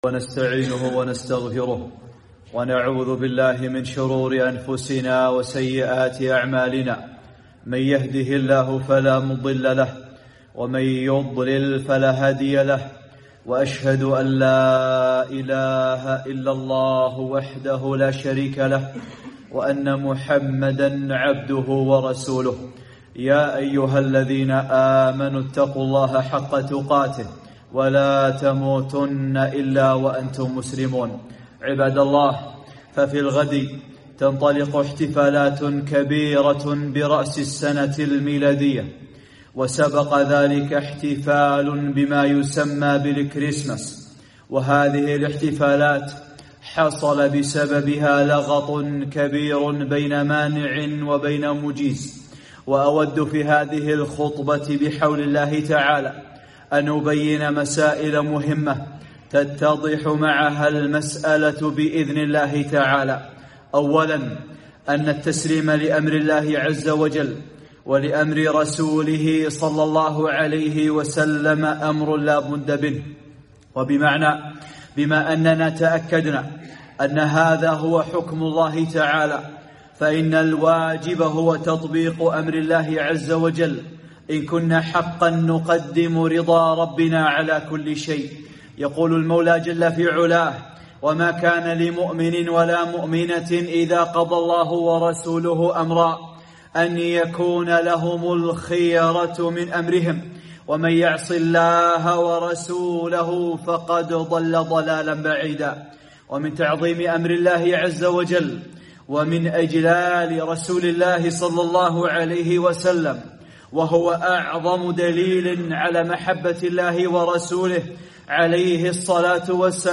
خطبة - وقفات مع الاحتفال برأس السنة الميلادية والكريسماس